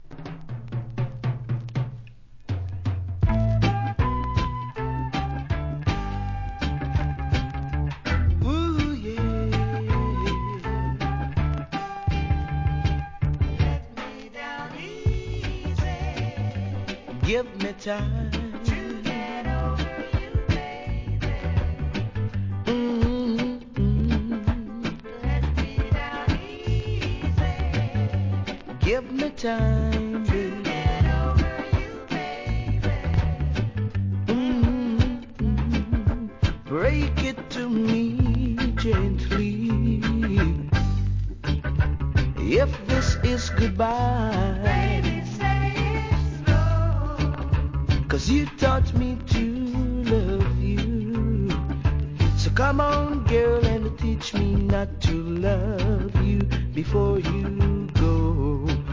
¥ 2,750 税込 関連カテゴリ REGGAE 店舗 ただいま品切れ中です お気に入りに追加 PRO.
タイトル通りソウルフルでハートウォーミングな名曲が揃ってます!!